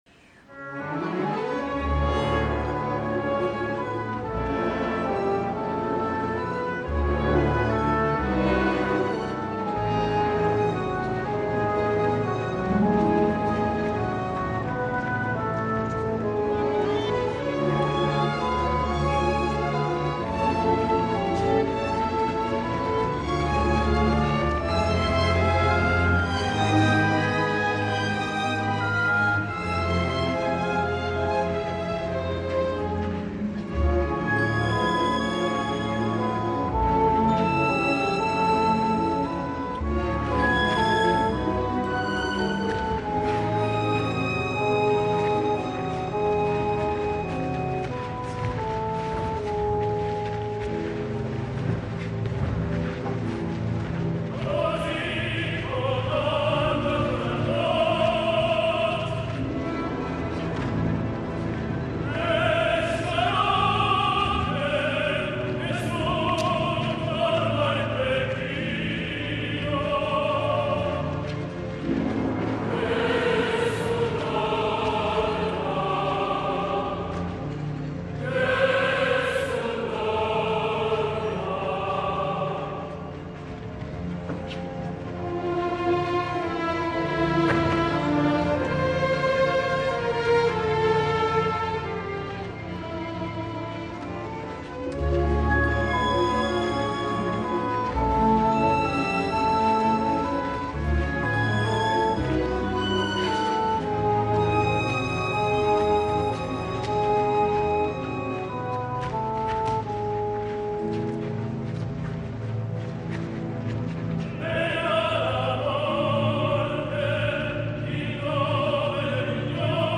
Per la segona representació, efectuada dilluns passat dia 31, Roberto Alagna va prendre precaucions i va fer un gran èxit, però el resultat ja ho jutjareu vosaltres mateixos, ja que la veu està al límit i a punt de trencar-se en diversos moments. Segurament la excessivament morosa direcció del veteraníssim mestre Michel Plasson, no el va ajudar gaire, amb uns temps amplíssims que encara posaven més al límit el fiato i el legato del tenor i en algun moment la concertació de l’òpera.
El rol és molt exigent i és quelcom més que un “Nessum dorma”, ja que hi ha molts passatges d’exigent tessitura central i greu que si no ho canta amb intel·ligència poden passar-li factura, de fet els aguts són sempre al límit del crit, però Alagna, no cal tornar-ho a dir, és un gran cantant, musical i amb suficients recursos expressius per salvar una representació  molt millorable.
Choregies d’Orange, 31 Juliol 2012
Calaf : Roberto Alagna
Orchestre National de France
Conductor : Michel Plasson